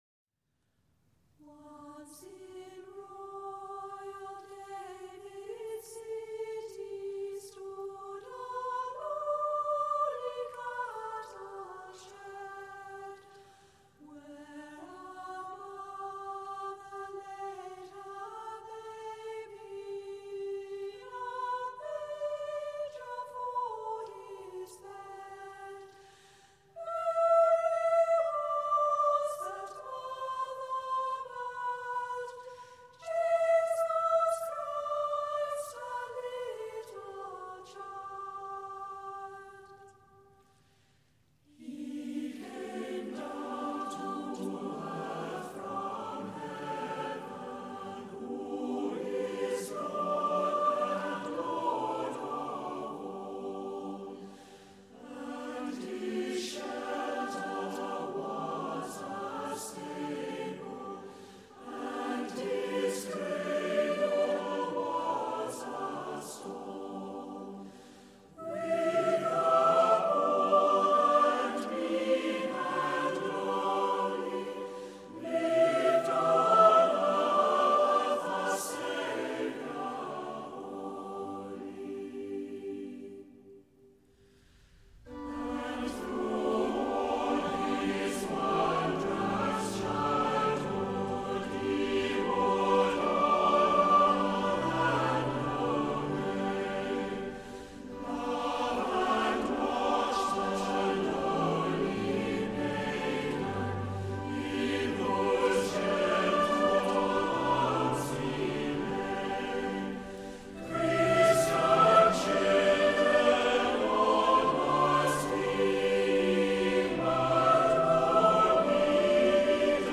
赞美诗 | Once In Royal David's City 大卫城中歌